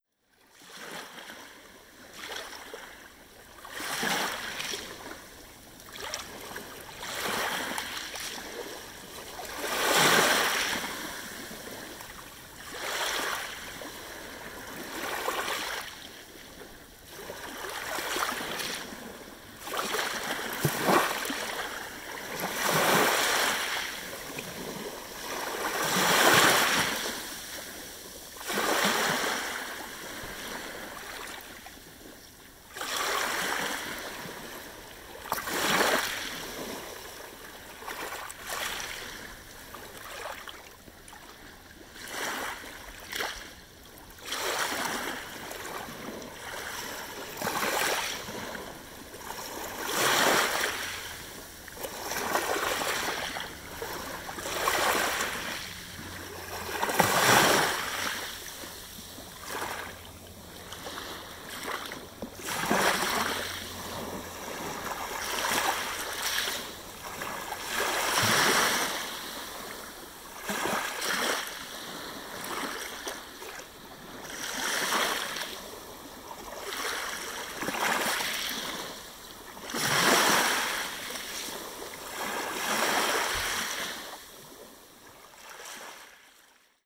Two recordings from a late-summer visit to Fillongley Provincial Park.
1. Waves
This time, I recorded some of the sounds down at the beach as the creek was pretty dry after the long, dry, and hot summer.
Fillongley-beach-waves_Edited.wav